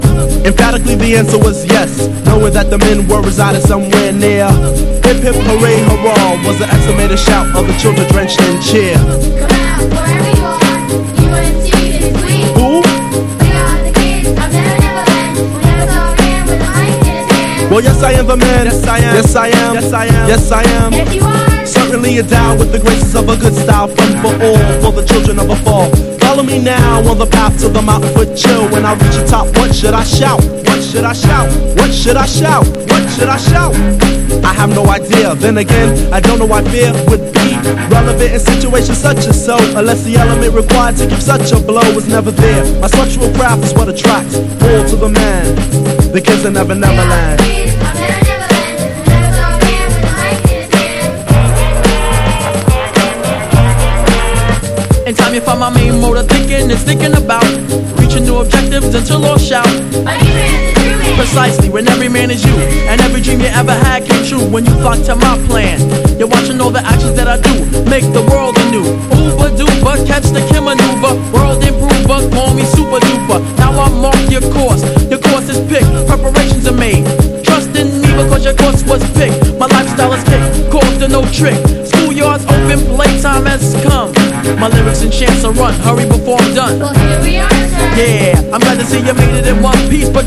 JAZZ / OTHER / JIVE / JUMP BLUES / RHYTHM & BLUES
ジャイヴィンな名ホンカーの噴火寸前のプレイを集めた黒人ダンス・コンピ！